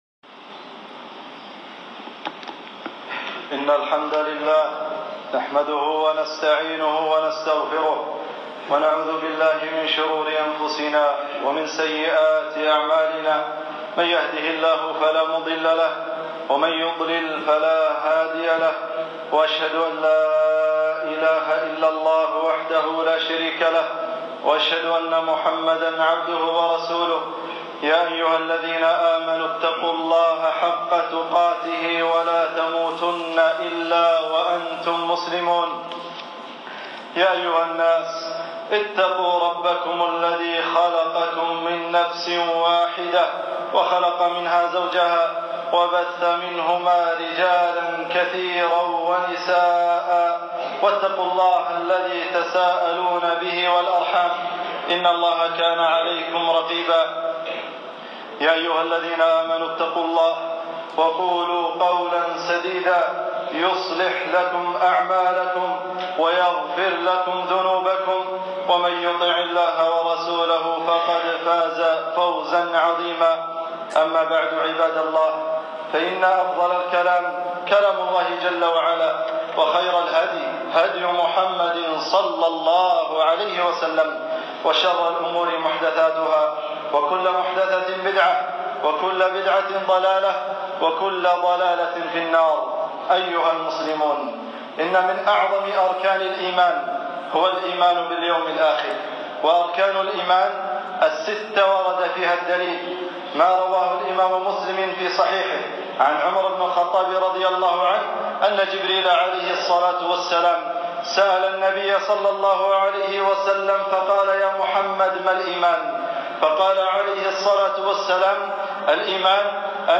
خطبة - يوم القيامة كأنك تراه 7-8-1440 هـ